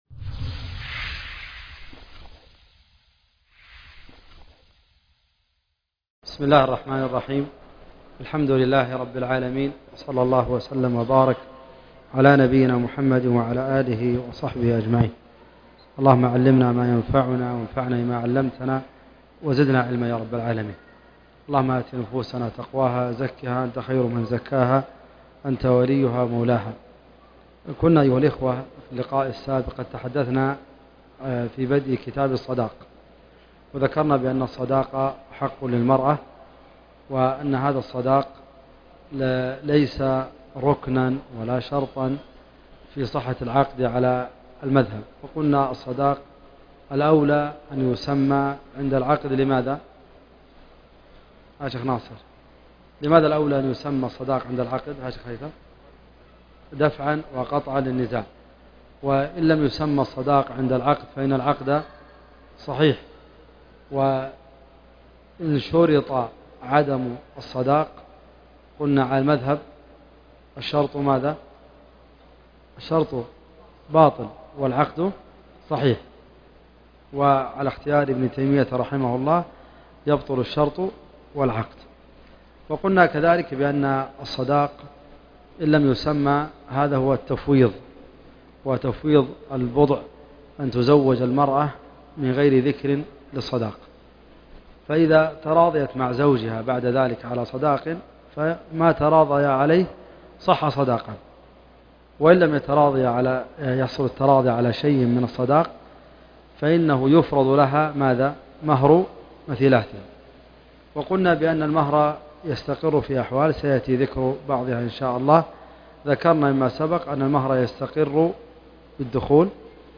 الدرس السادس والخمسون- شرح عمدة الفقه